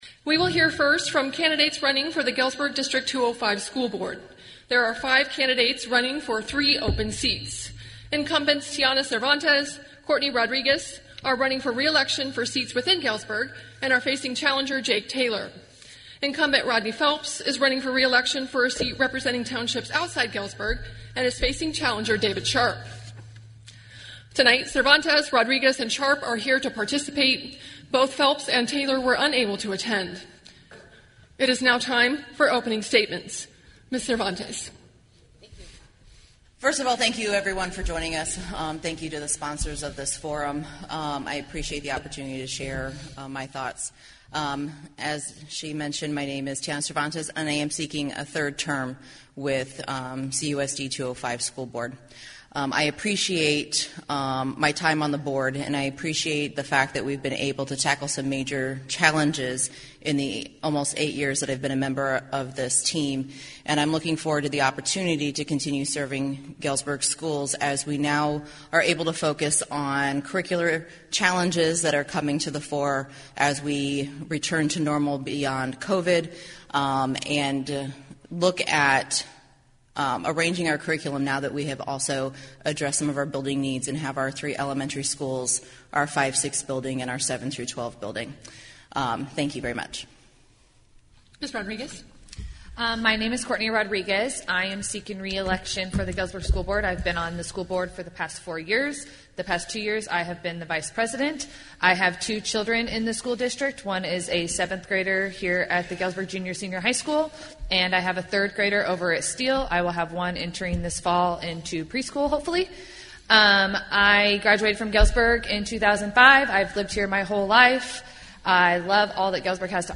Candidates for Galesburg City Council and District 205 School Board participated in a Galesburg Candidate Forum on March 14 in Hegg Performing Arts Center at Galesburg High School.